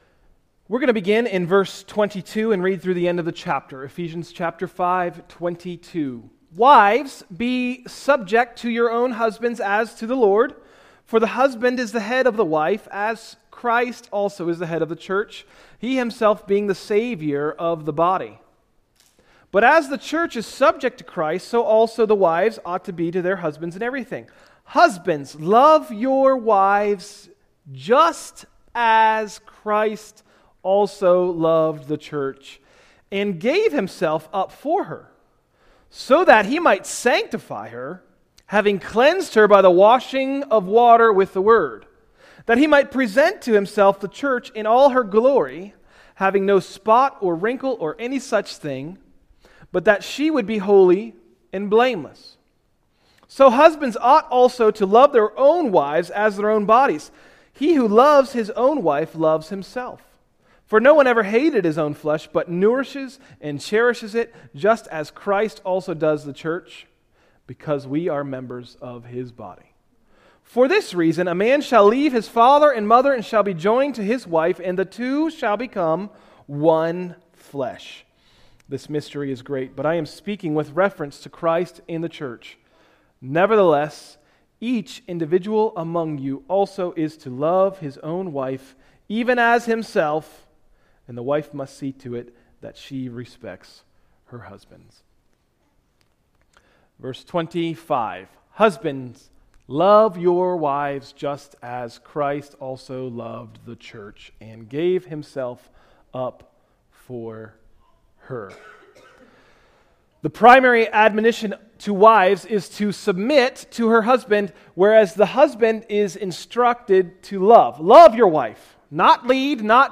Message: “A Winning Marriage pt.2” – Tried Stone Christian Center